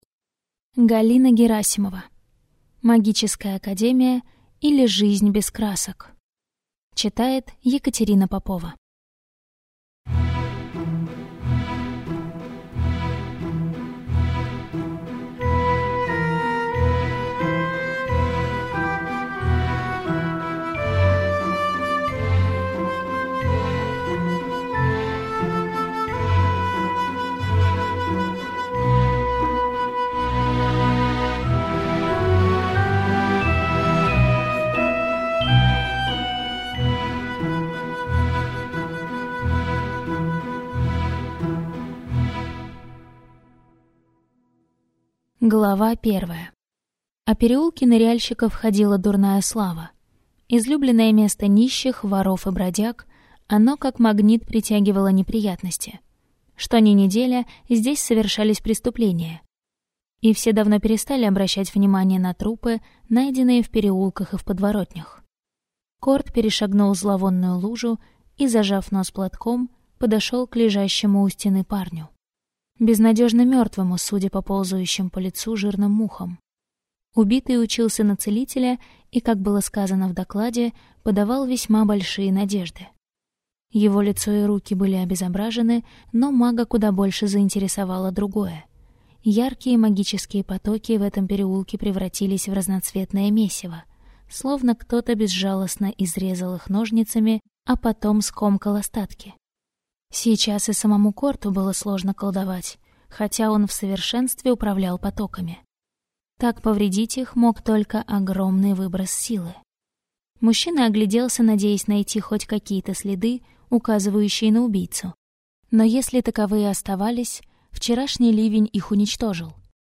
Аудиокнига Магическая Академия, или Жизнь без красок | Библиотека аудиокниг